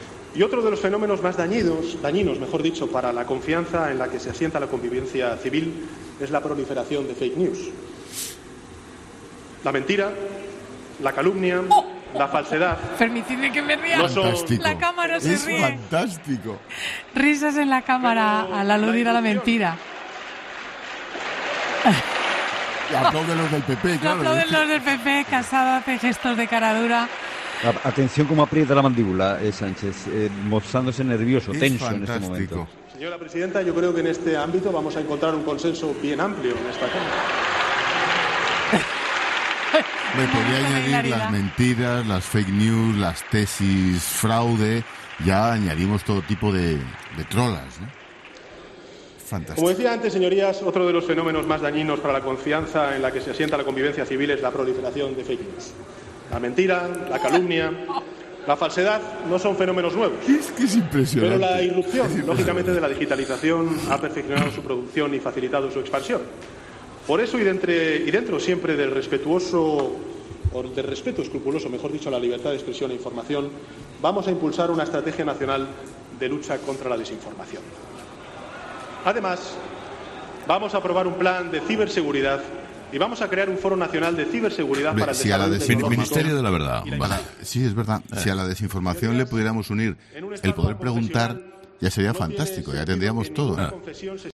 AUDIO: La propuesta de Sánchez de luchar contra las fake news y la mentira desata las carcajadas en el Congreso